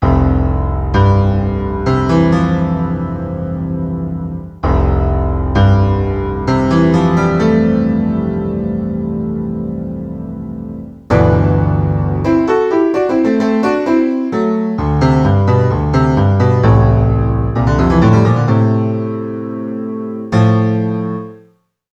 Piano. Breve pieza.
instrumento musical
piano
Sonidos: Música